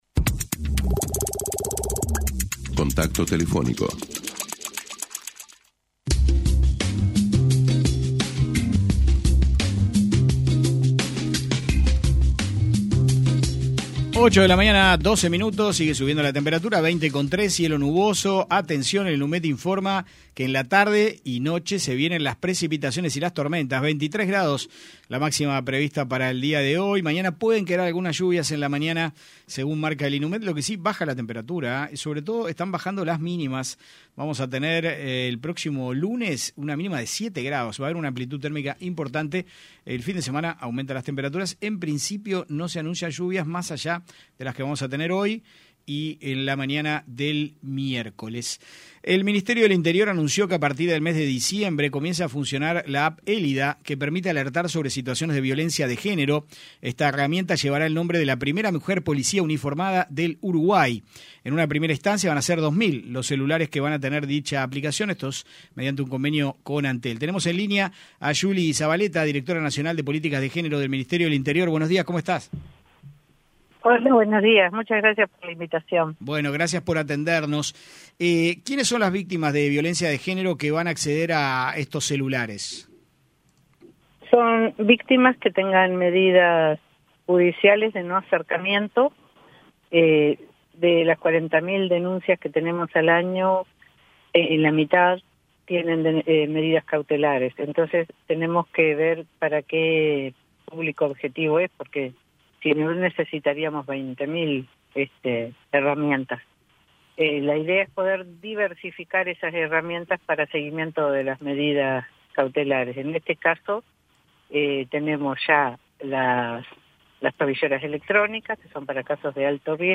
El Ministerio del Interior anunció que a partir del mes de diciembre comienza a funcionar la app Élida que permite alertar sobre situaciones de violencia de género. En diálogo con 970 Noticias, la directora nacional de Políticas de Género de la cartera, July Zabaleta, explicó a quién está dirigida y cómo va a funcionar.